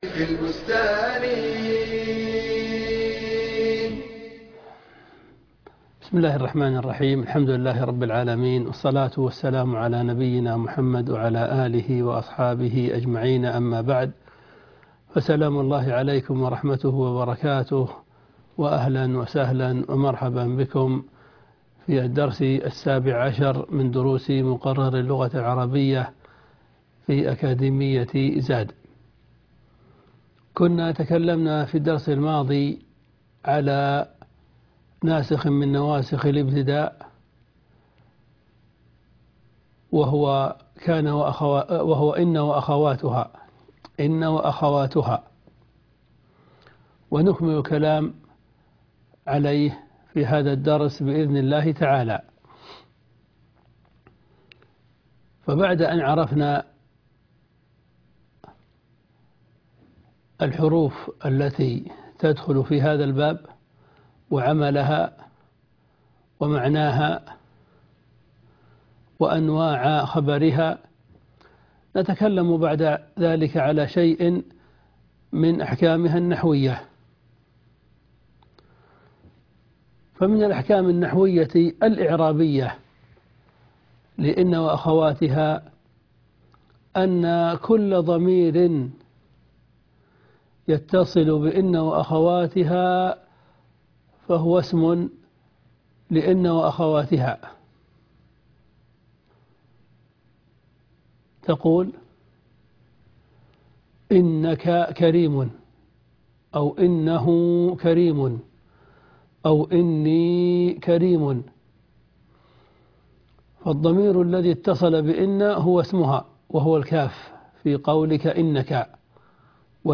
المحاضرة السابعه عشر _ ان واخواتها_